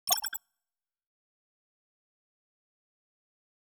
Base game sfx done
FuturisticPopup.wav